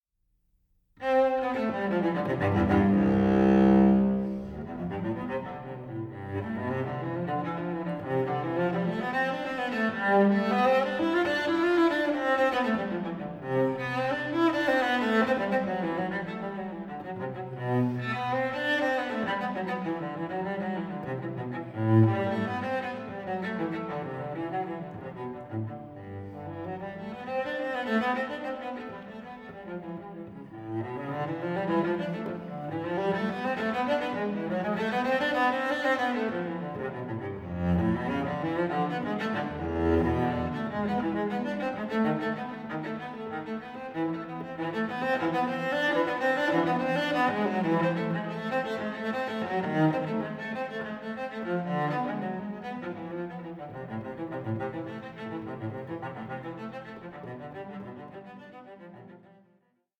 for solo cello piccolo
from the low bass to the soprano stratosphere.